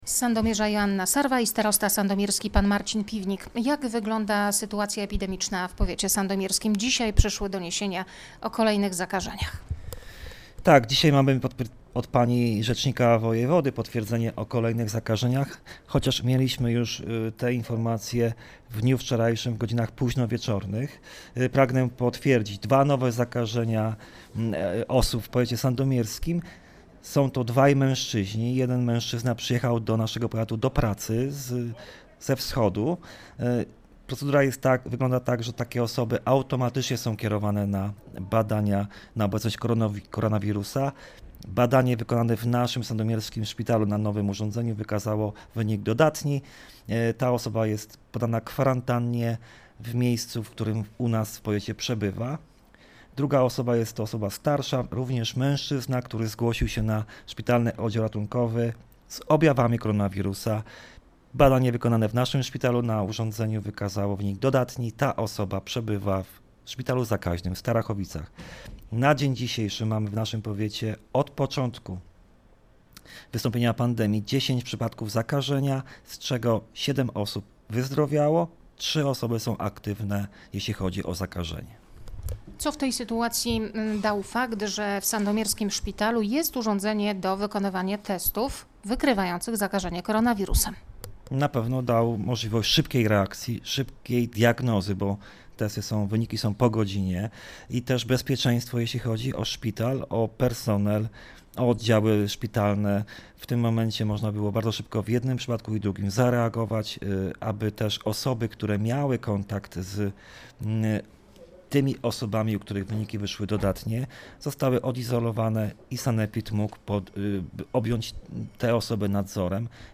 Mówi starosta Marcin Piwnik: